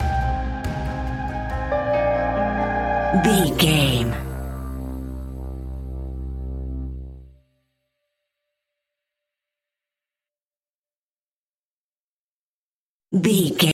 In-crescendo
Thriller
Aeolian/Minor
scary
tension
ominous
dark
haunting
eerie
piano
strings
synthesiser
percussion
drums
brass
horror music